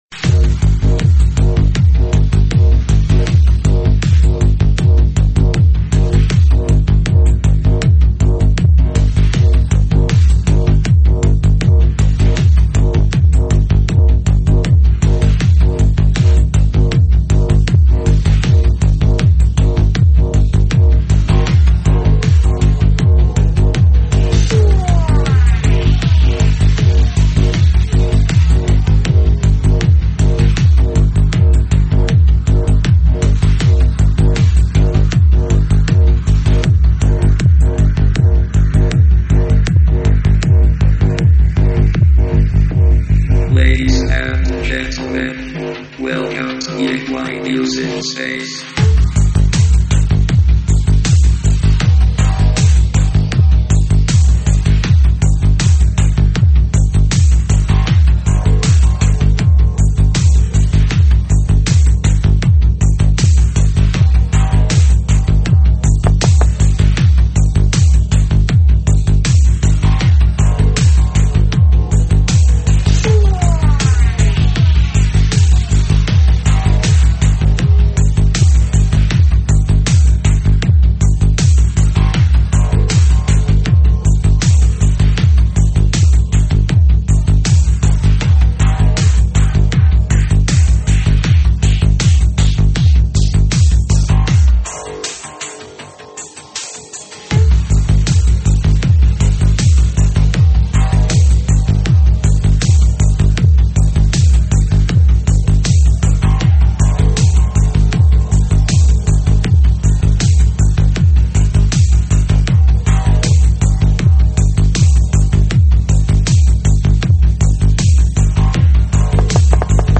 栏目： 慢摇舞曲